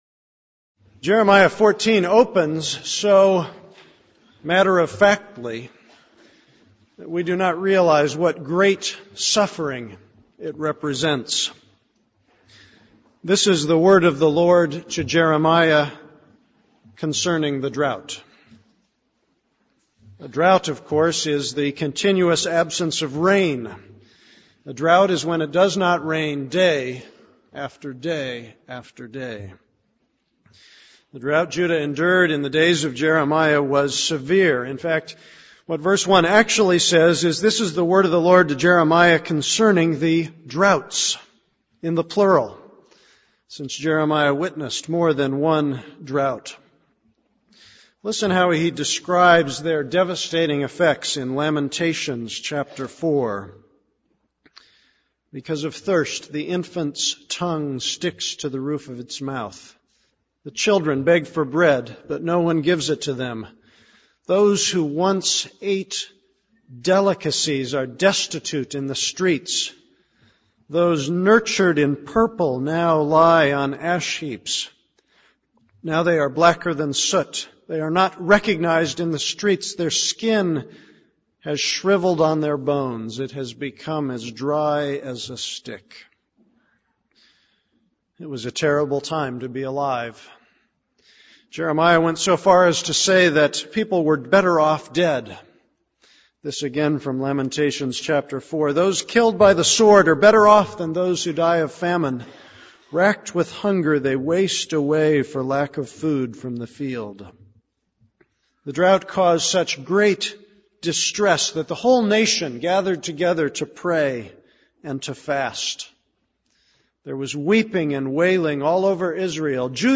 This is a sermon on Jeremiah 14:1-21.